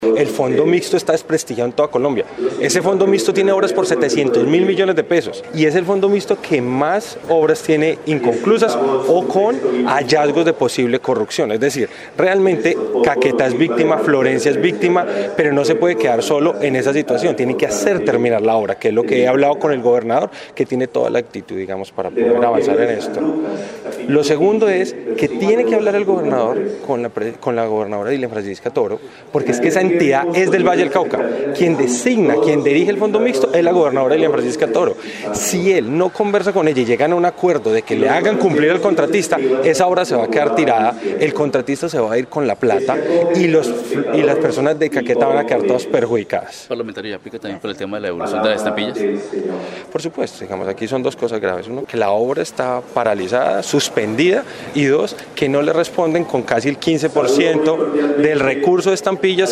Proponen dialogo entre gobernaciones para dirimir tema Doble Calzada SENA – Florencia. Duvalier Sánchez, representante a la cámara por el Valle del Cauca, dice que la mandataria de su departamento, debe obligar al Fondo Mixto a culminar la obra, y que esta no sea un ´elefante blanco´.